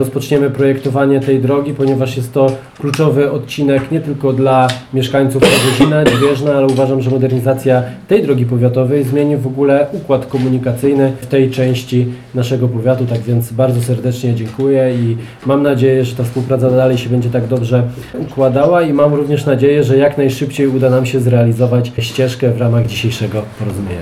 – mówił Piotr Jakubowski, Wójt Gminy Radzanowo.